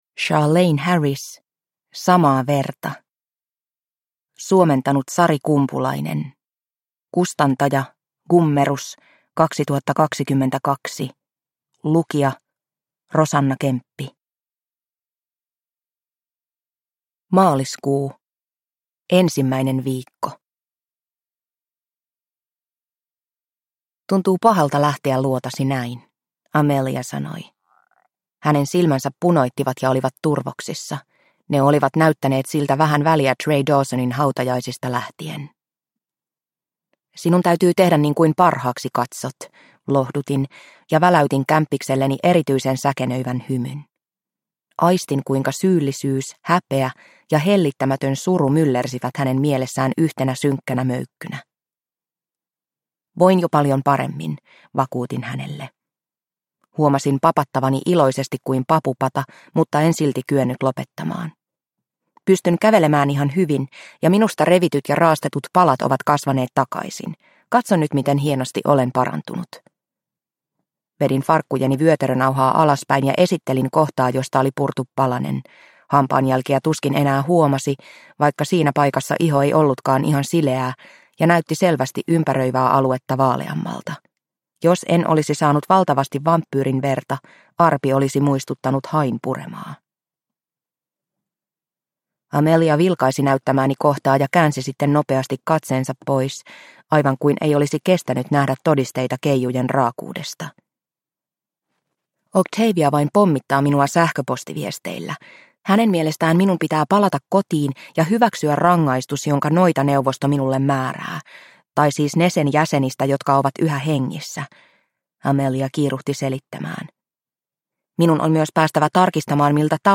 Samaa verta – Ljudbok – Laddas ner